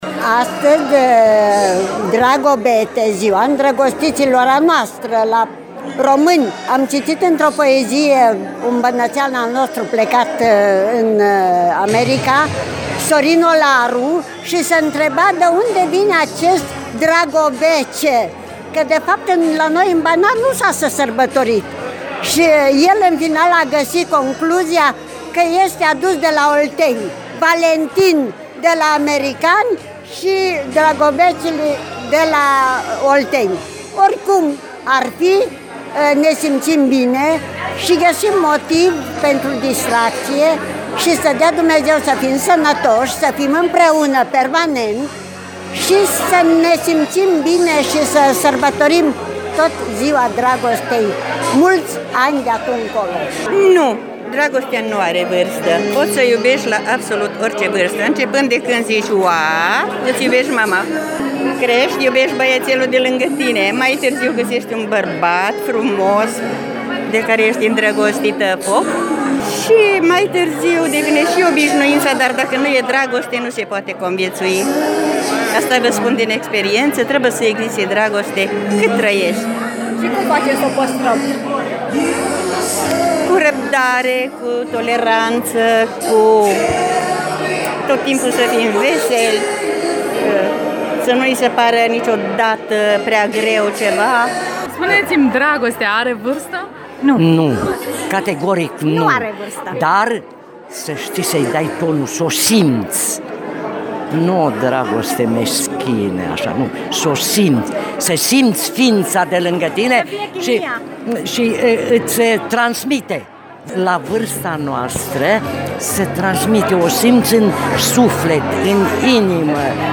Seniorii ne-a spus printre acorduri romantice sau joc de voie bună, povești de viață impresionante pe care le-am descoperit dialogând cu aceștia.
vox-pensionari-de-Dragobete-pentru-site.mp3